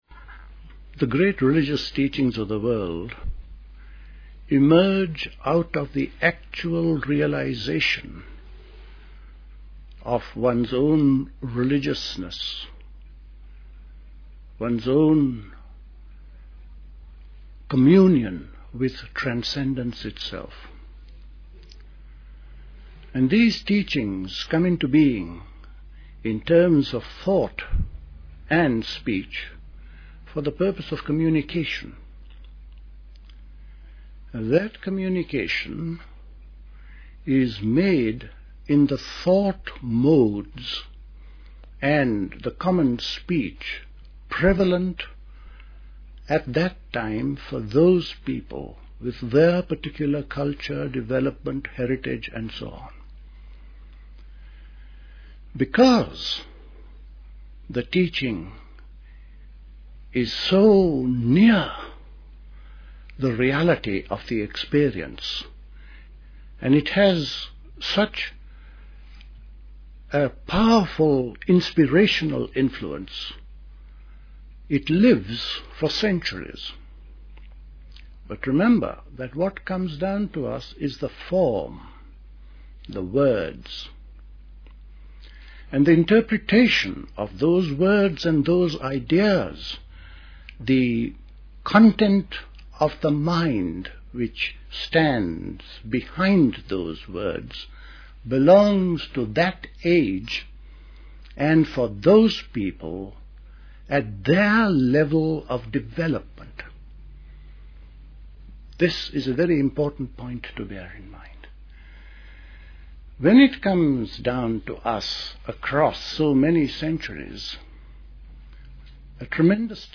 The true corollary of karma is a life rhythm in constant manifestation. The main talk is followed by five minutes of meditation followed by a few concluding words.